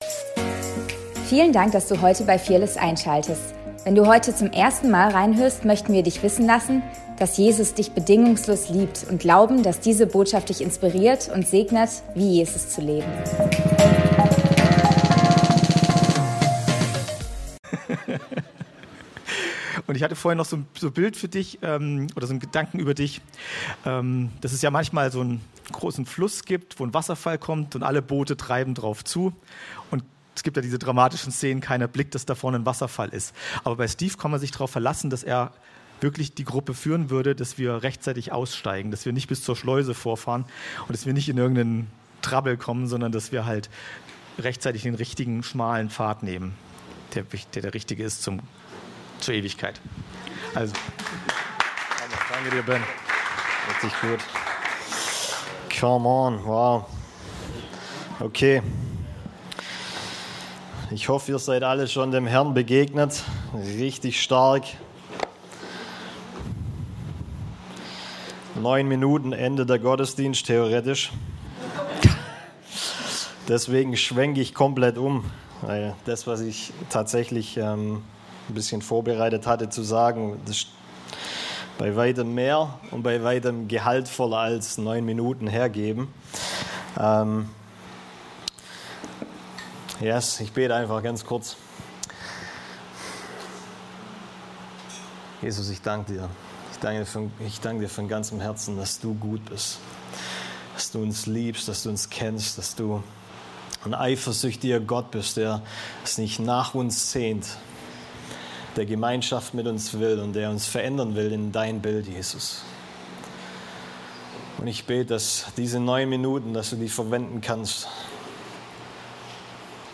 Fearless Church - Predigt vom 06.07.2025